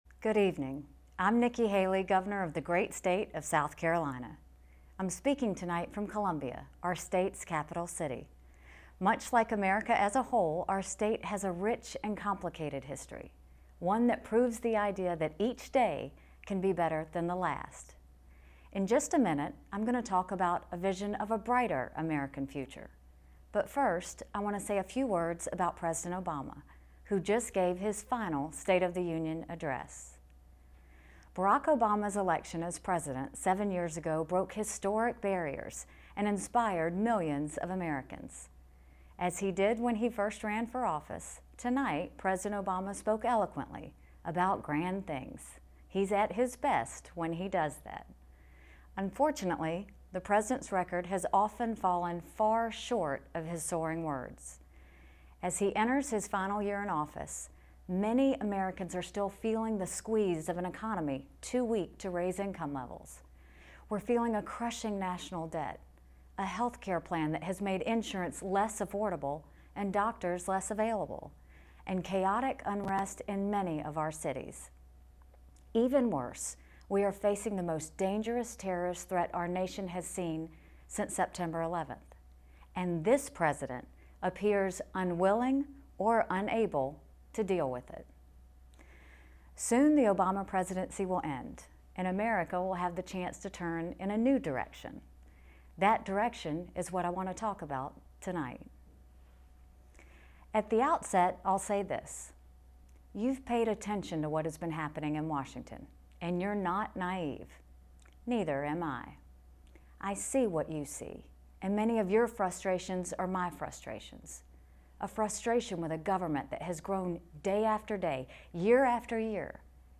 Transcript: Gov. Nikki Haley's Republican Address To The Nation
Read South Carolina Gov. Nikki Haley's Republican address that follows President Obama's final State of the Union speech.